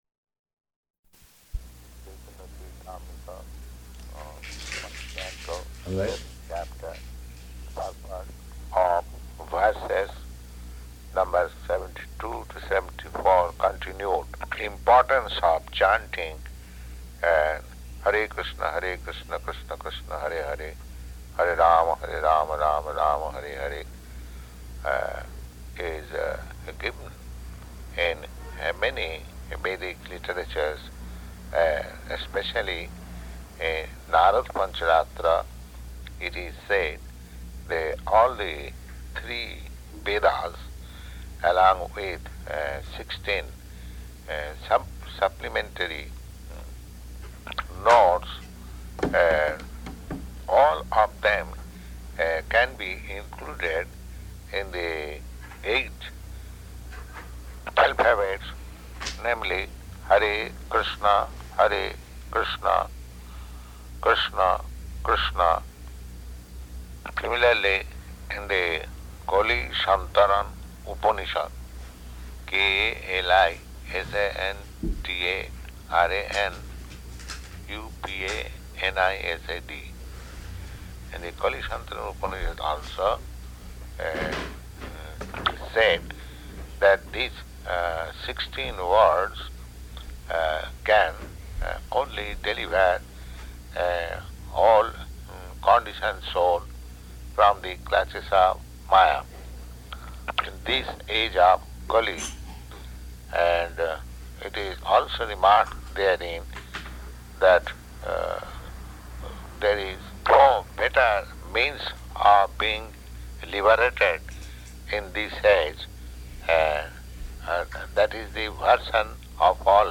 Śrī Caitanya-caritāmṛta Dictation
Śrī Caitanya-caritāmṛta Dictation --:-- --:-- Type: Other Dated: October 21st 1968 Location: Seattle Audio file: 681021DT-SEATTLE.mp3 Prabhupāda: Śrī Caitanya-caritāmṛta , first Canto.]